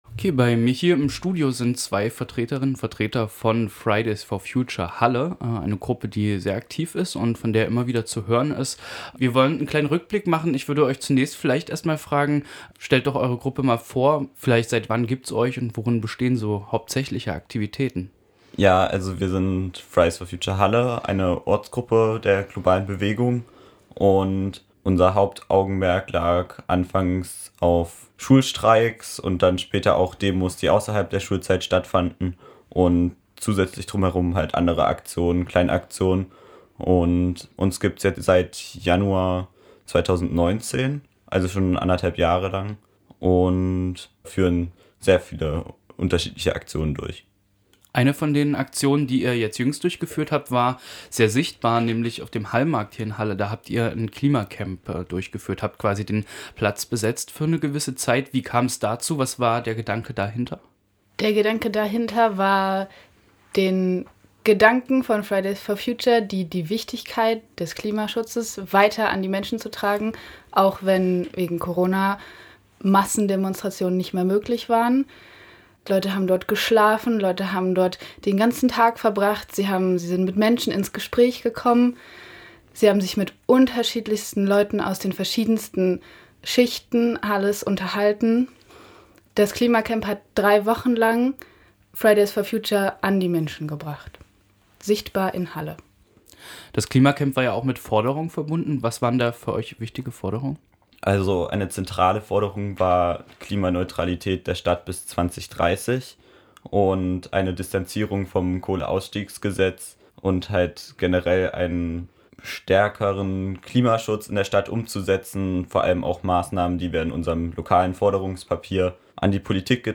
Dafür haben wir auch mit